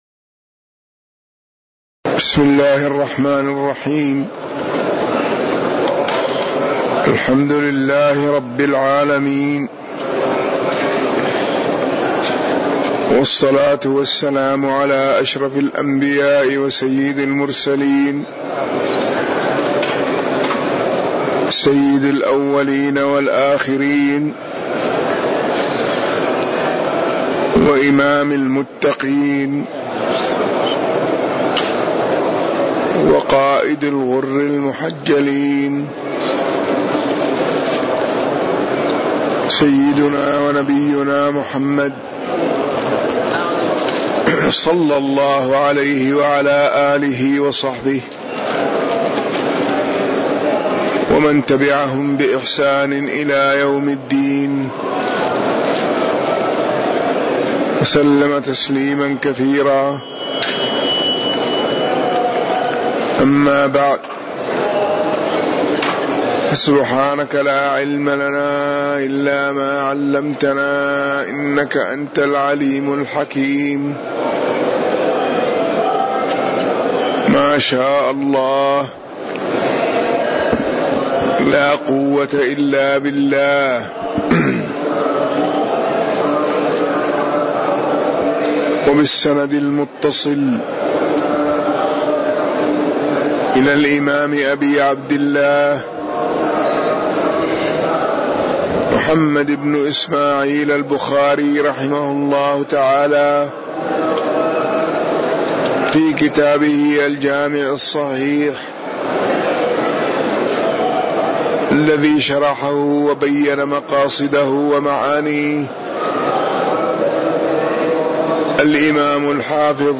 تاريخ النشر ٢٤ ربيع الأول ١٤٣٩ هـ المكان: المسجد النبوي الشيخ